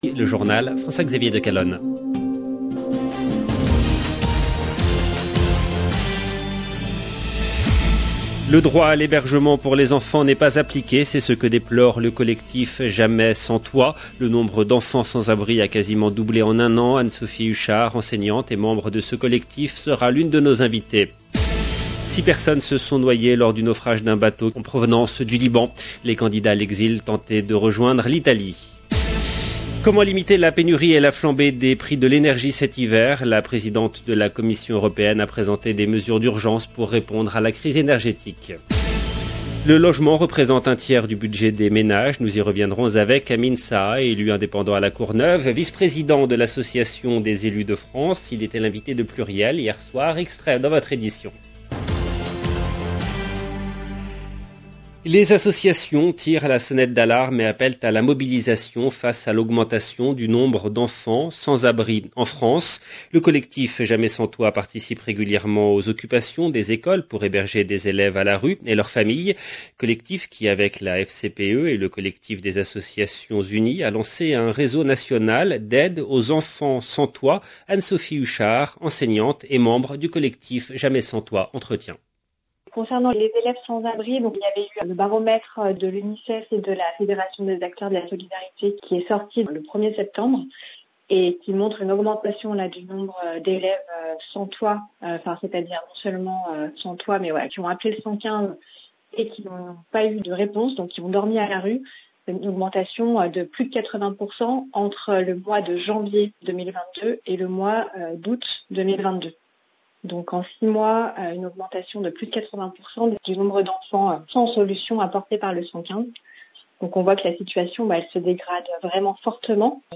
Nous y reviendrons avec Amine Saha, élu indépendant à La Courneuve, vice-président de l’Association des Elus de France. Il était l’invité de Pluriel hier soir.